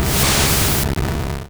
Cri d'Alakazam dans Pokémon Rouge et Bleu.